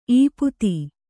♪ īputi